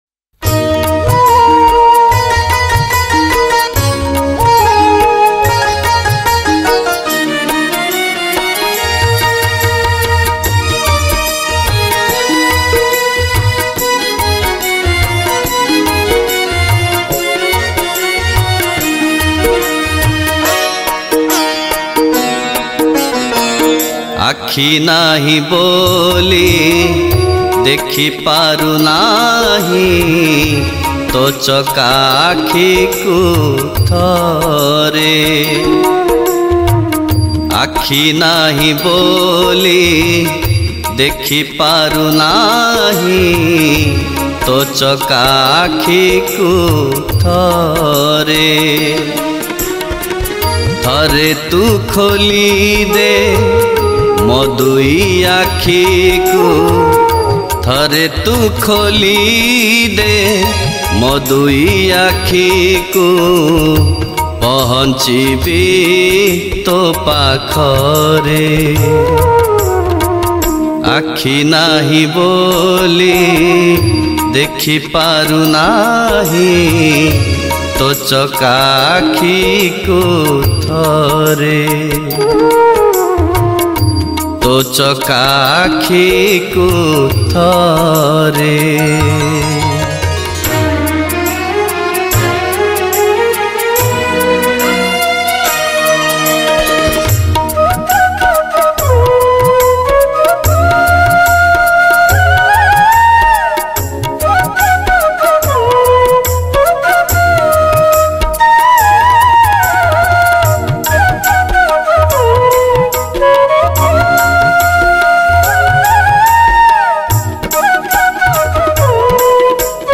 Odia Bhajan Song 2022 Songs Download